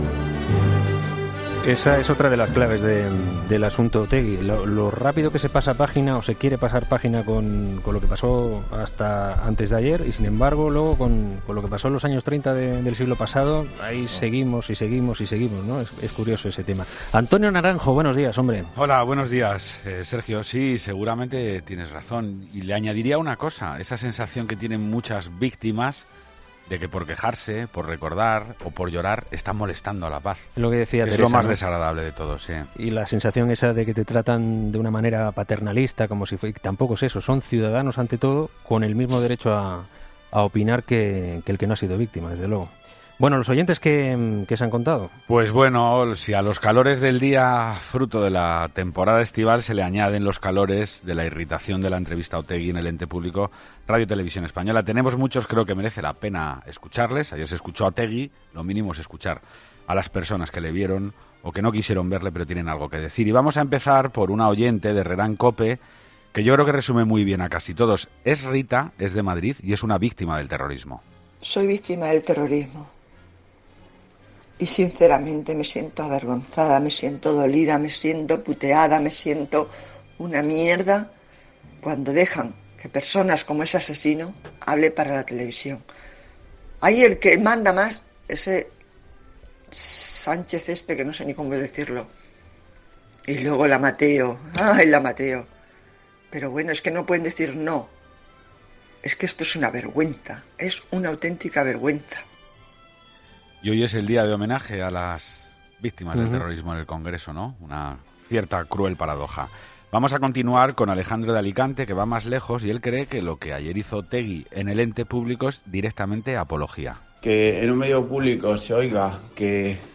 La tertulia de los oyentes